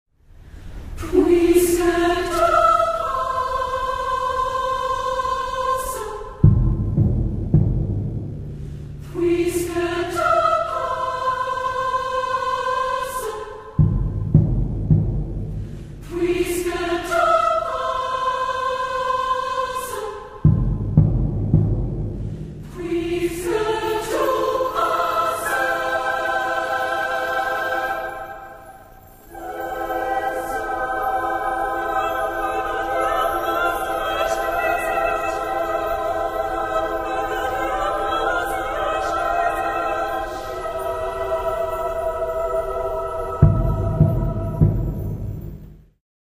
• Voicing: ssaa
• Solo: SS
• Accompaniment: percuussion